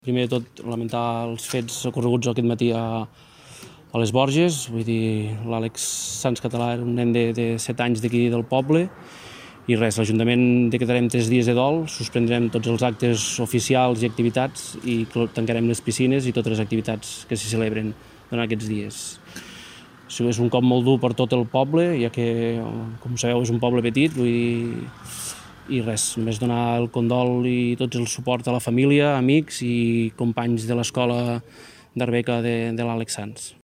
Jordi Gaya, alcalde dels Omellons | Descarrega'l com a: | MP3